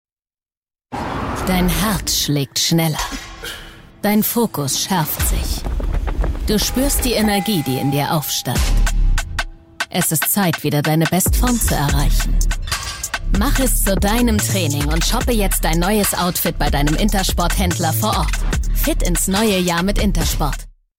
sehr variabel, hell, fein, zart, markant, plakativ, dunkel, sonor, souverän
Commercial (Werbung)